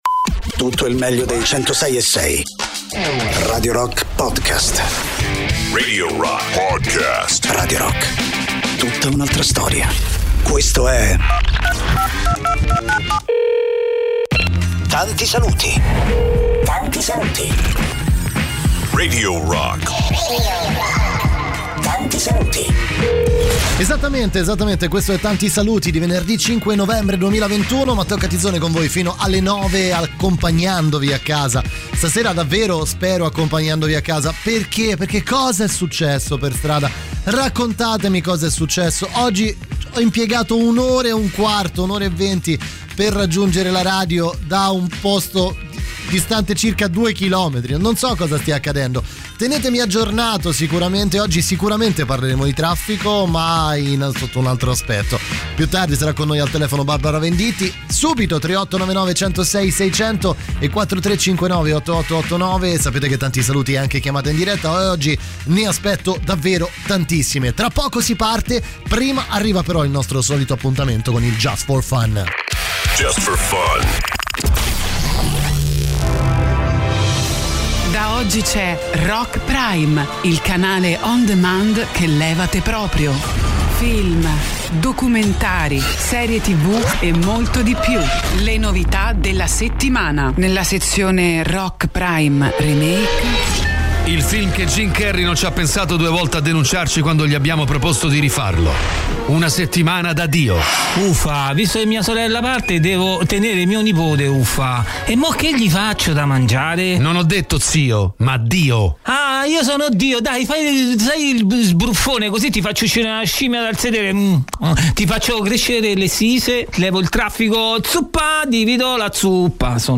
in diretta dal lunedì al venerdì, dalle 19 alle 21, con “Tanti Saluti” sui 106.6 di Radio Rock.